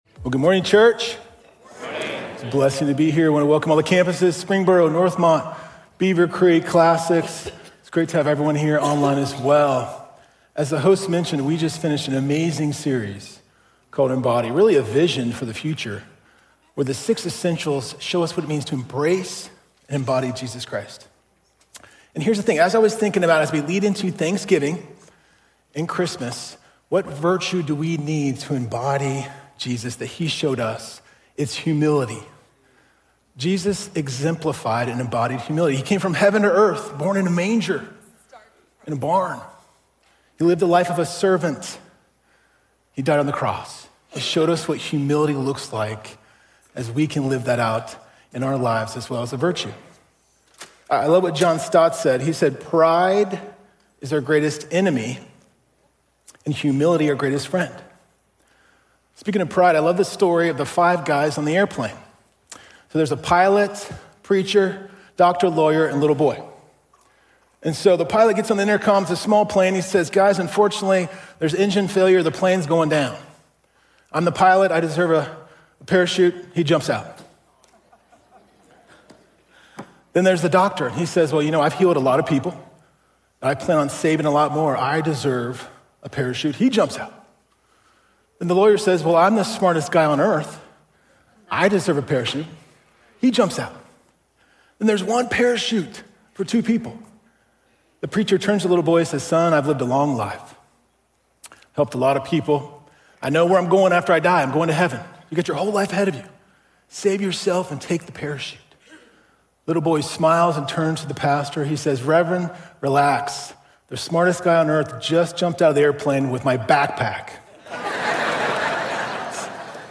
Healed-by-Humility_SERMON.mp3